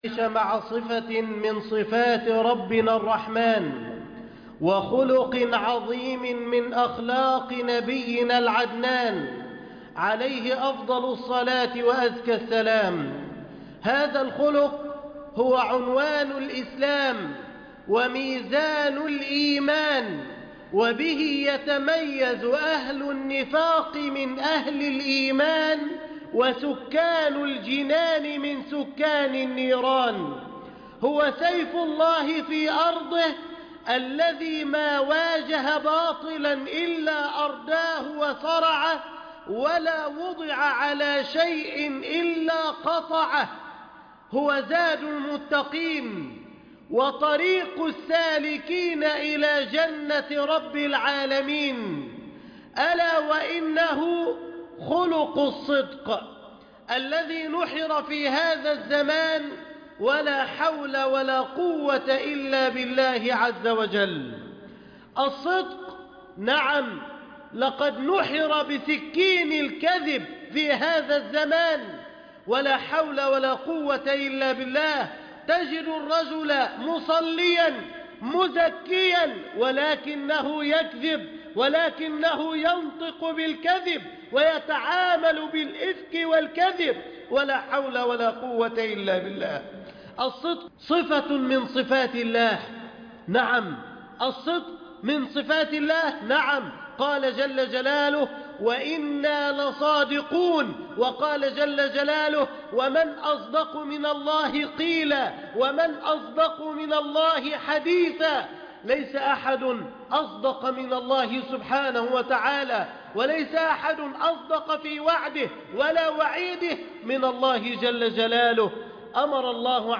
خطبة عن الصدق